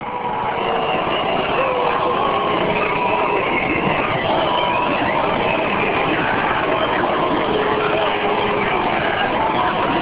Later, even thousands people were standing there and waiting for the President and the government.